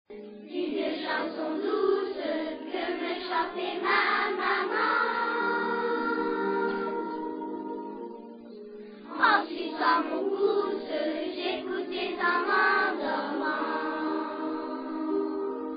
300 élèves de Dunkerque
Vu le nombre des enfants, le résultat n'a pas la qualité d'un véritable travail de chorale, mais chacun essaie d'y mettre de la bonne volonté et des notes plutôt justes !
C'est la répétition seulement !